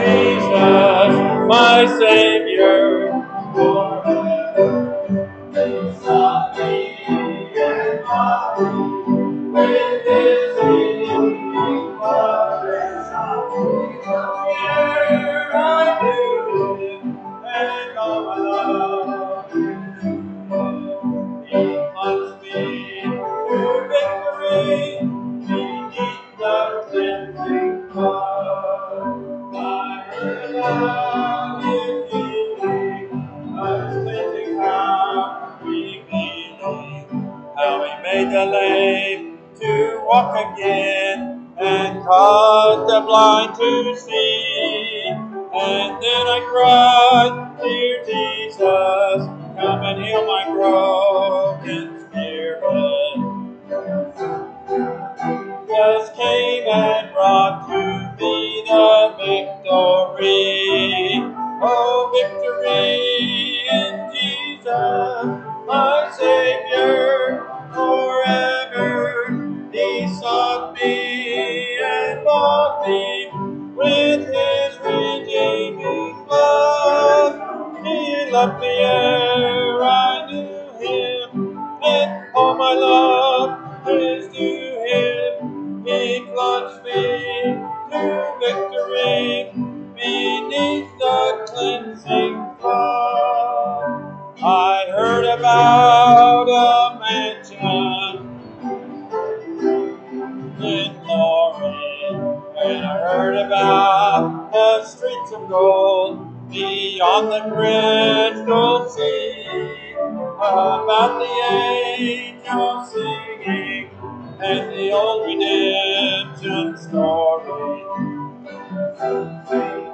Sermons Sort By Date - Newest First Date - Oldest First Series Title Speaker Peter The Book 1 Peter.